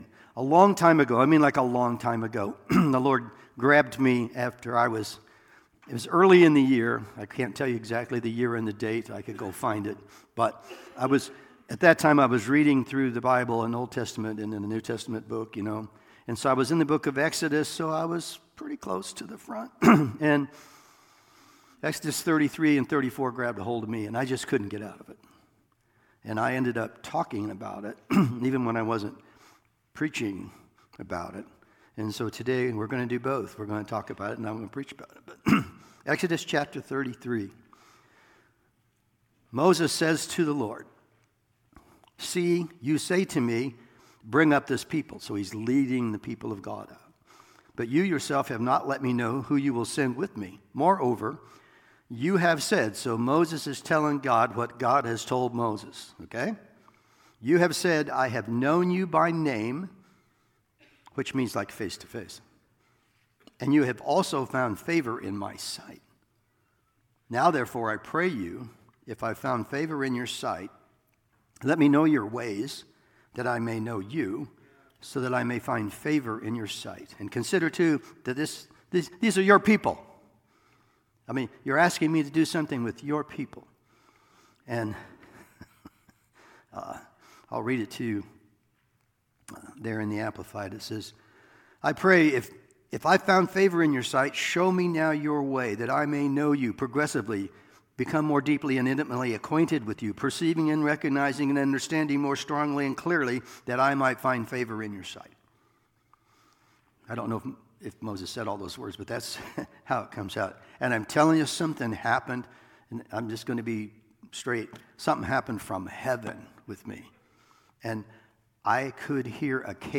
2 Corinthians 5:17 Service Type: Sunday Morning Sermon Download Files Notes